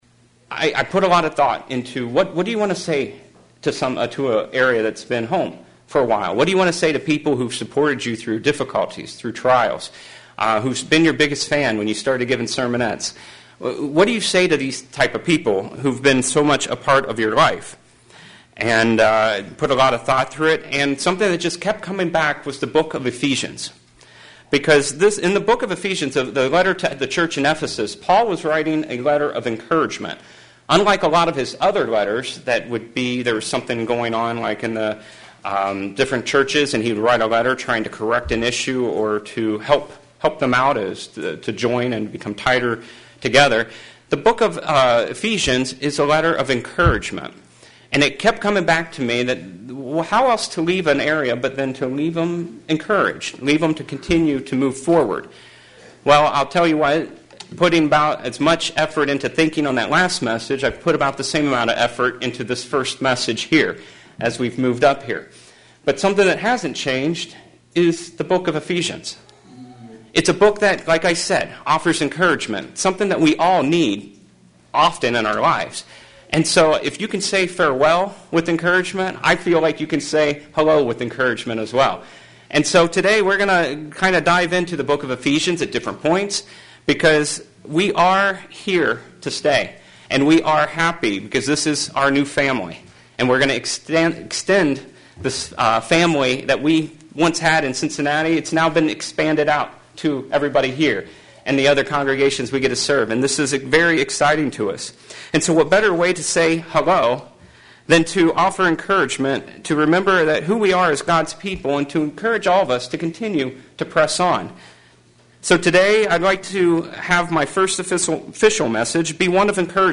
Today, we are going to look at the encouragement for the Ephesians. sermon Transcript This transcript was generated by AI and may contain errors.